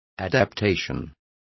Also find out how adecuacion is pronounced correctly.